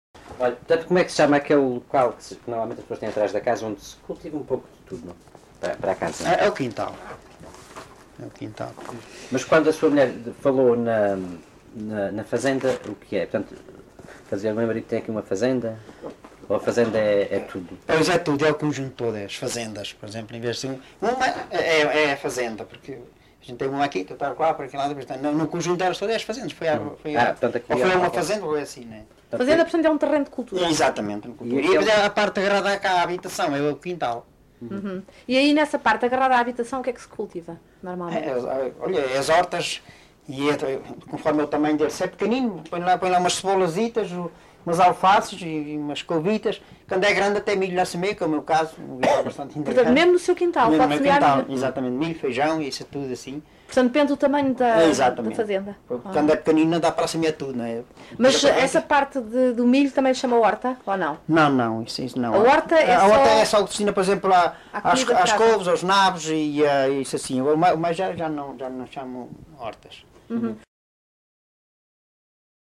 Moita do Martinho, excerto 4
LocalidadeMoita do Martinho (Batalha, Leiria)